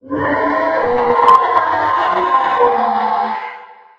4aef571f59 Divergent / mods / Soundscape Overhaul / gamedata / sounds / monsters / poltergeist / die_0.ogg 23 KiB (Stored with Git LFS) Raw History
die_0.ogg